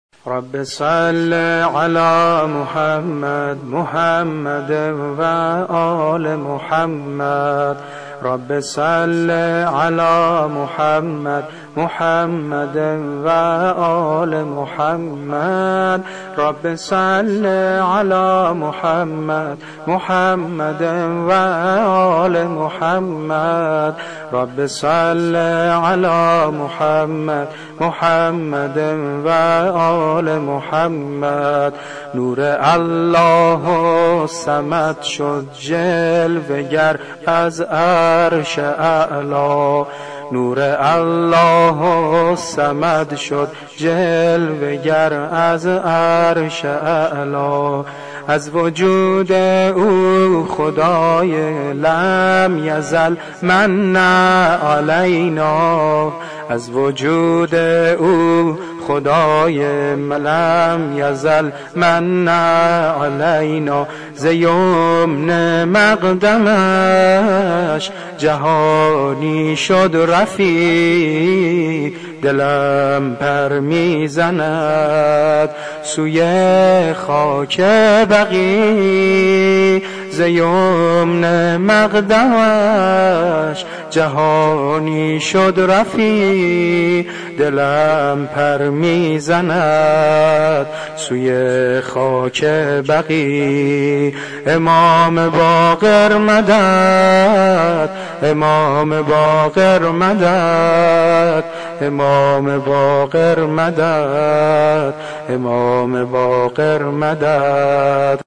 ولادت حضرت اباالفضل العباس (ع)